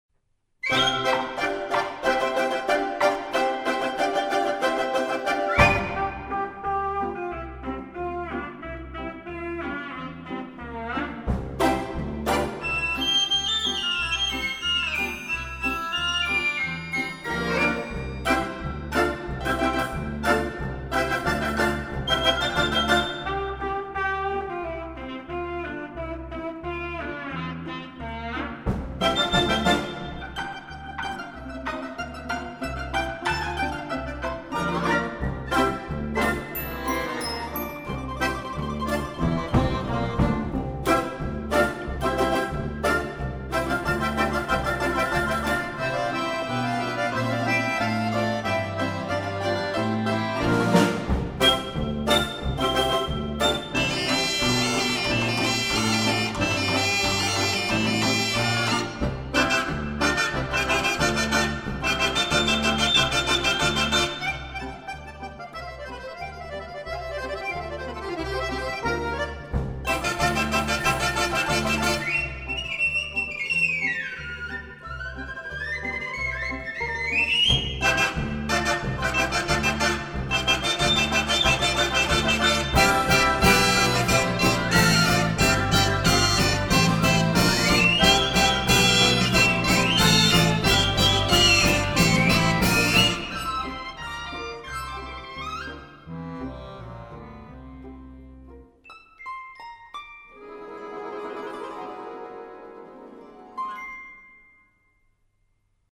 Russian Folk Instruments Soloist's Band